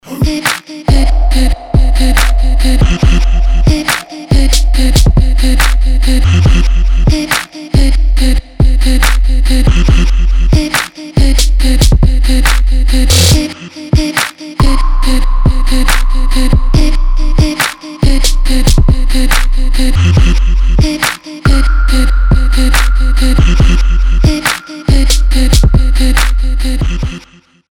Electronic
без слов
Trap
Стиль: Arabic Trap